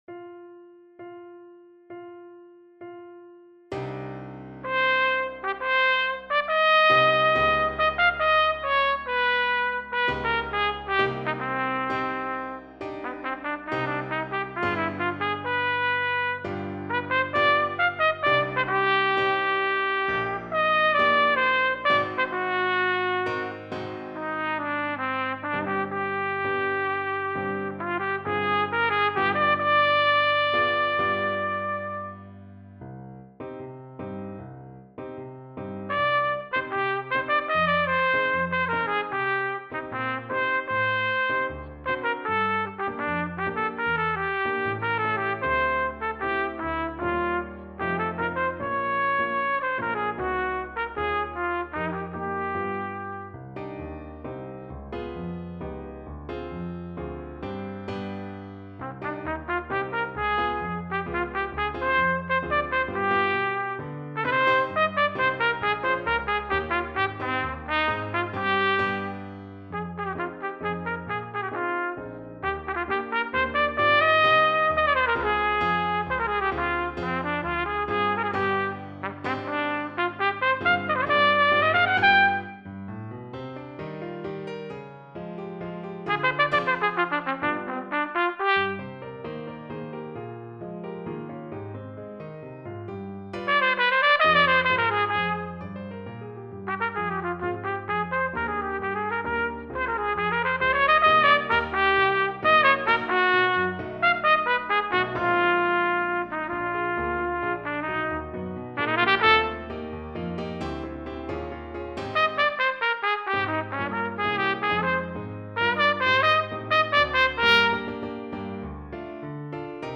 andalousia-trp-piano.mp3